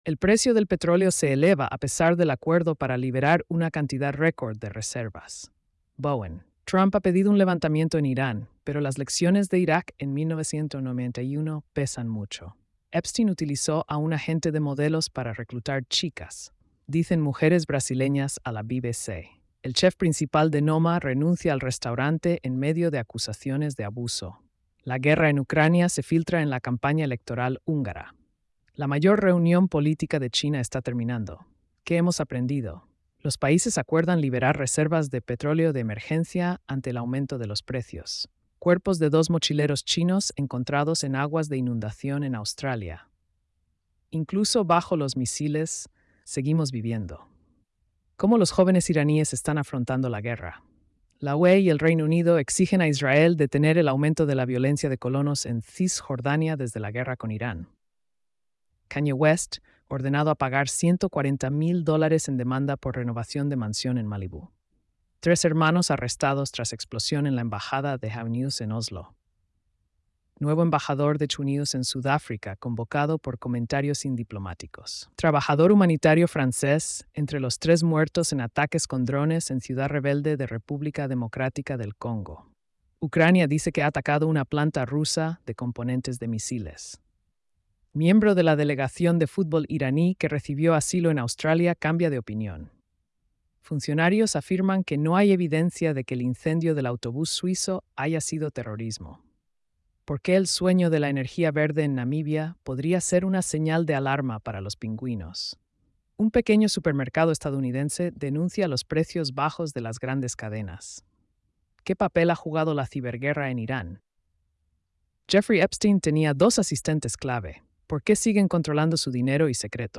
🎧 Resumen de noticias diarias. |